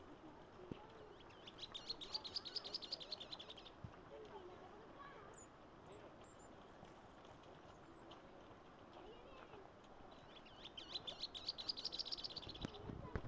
Canastero Estriado (Pseudasthenes humicola)
Lifer! dos ejemplares juntos que contestaron muy bien al playback!
Condición: Silvestre
Certeza: Fotografiada, Vocalización Grabada
Wed-12.48-dusky-canastero.mp3